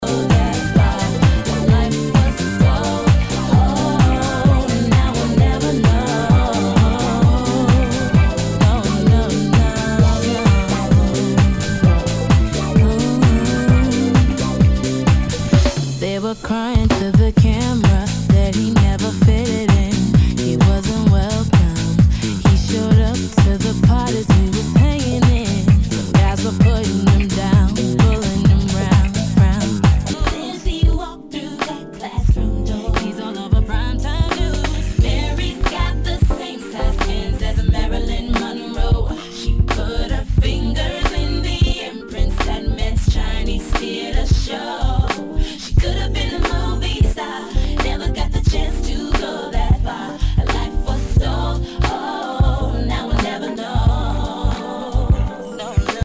HOUSE/TECHNO/ELECTRO
ナイス！R&B / ヴォーカル・ハウス・ミックス！